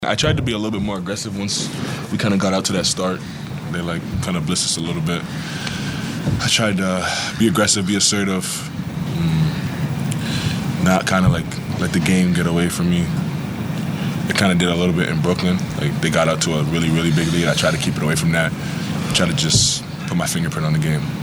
SGA talks about his aggressive approach after an early good start from the Rockets.